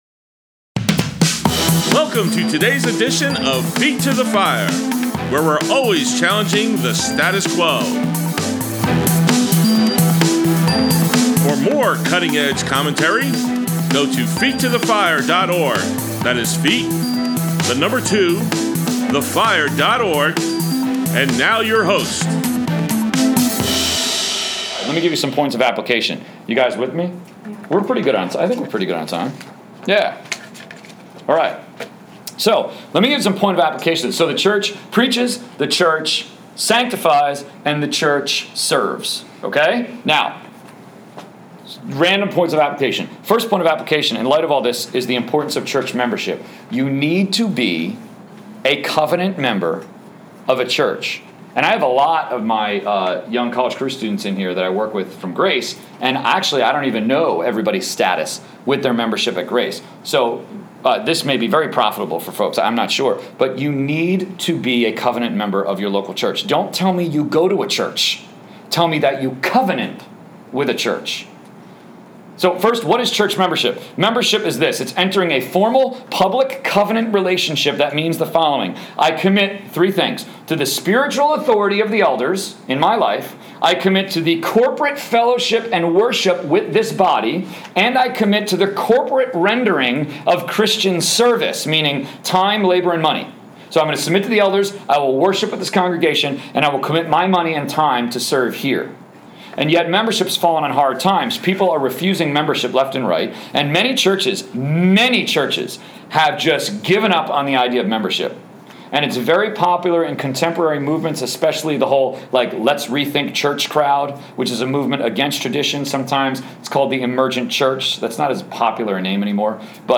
Brookdale CRU Equip Conference at Lincroft Bible Church